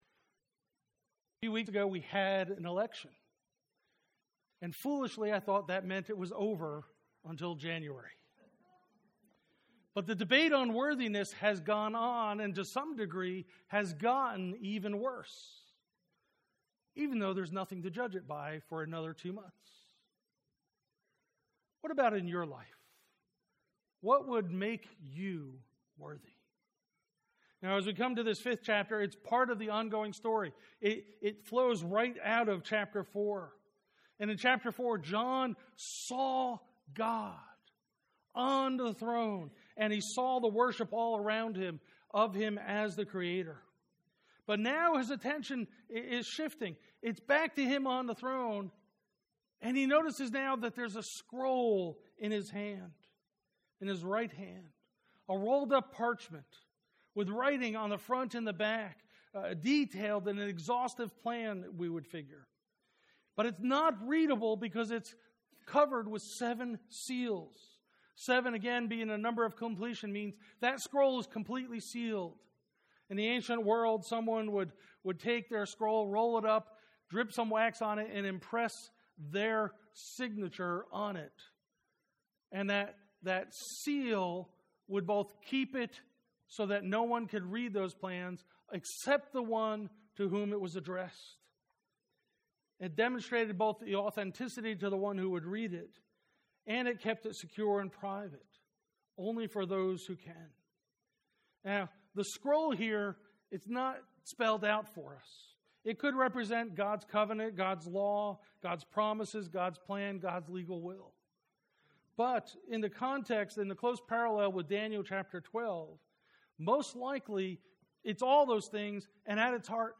Trinity Presbyterian Church Sermons